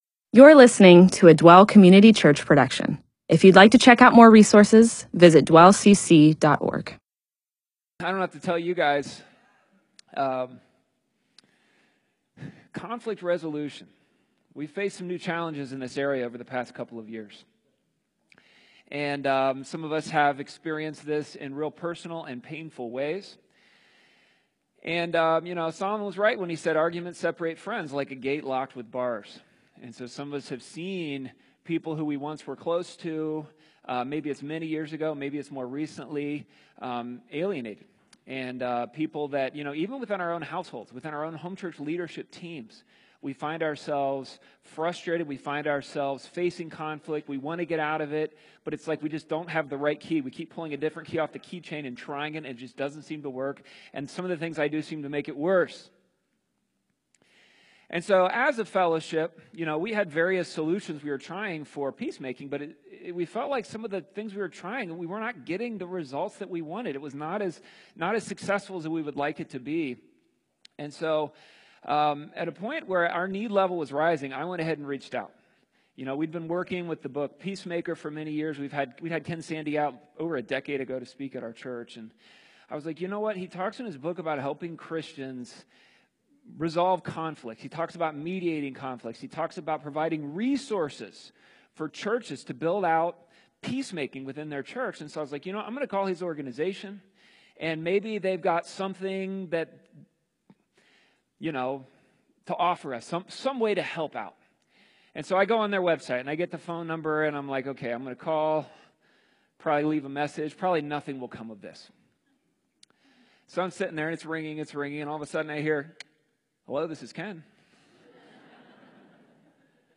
MP4/M4A audio recording of a Bible teaching/sermon/presentation about Exodus 18:5-20.